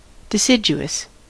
Ääntäminen
IPA : /dɪˈsɪdʒʊəs/